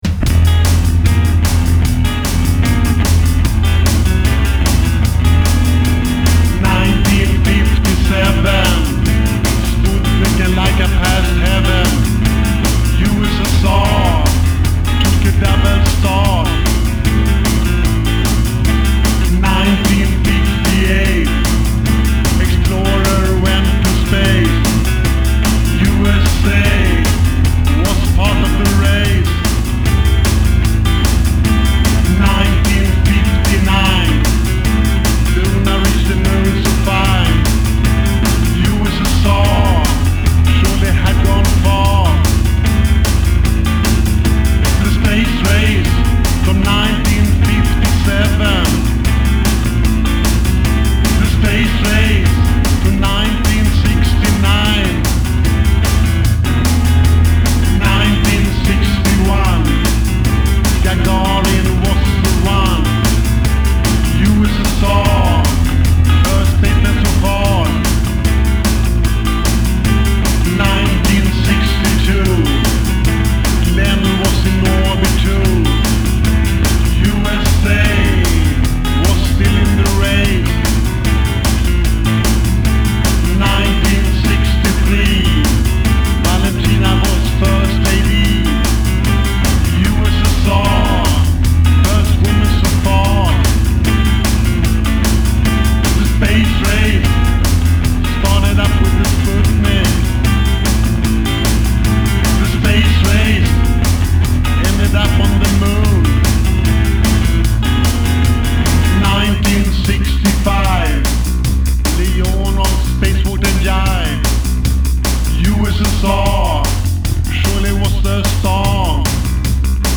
GRUNDRIFF: AABbBCCBBb